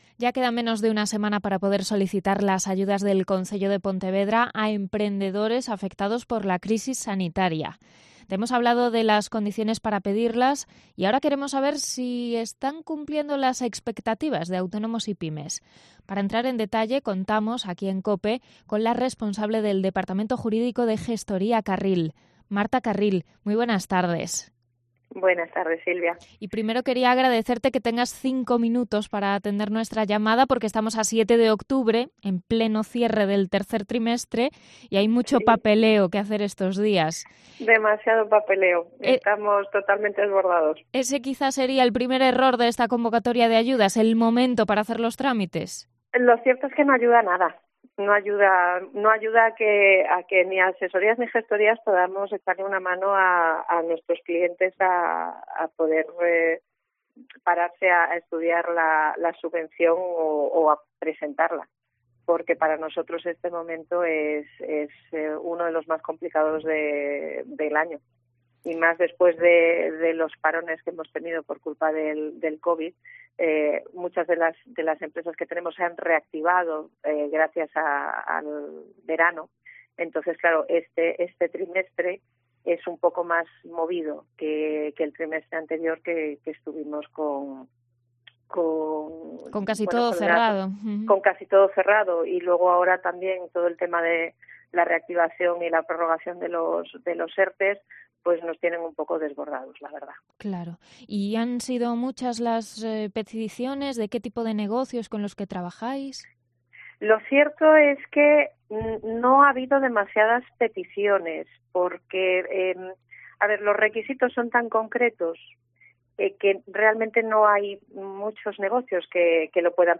Entrevista sobre las ayudas del Plan Supera20 del Concello de Pontevedra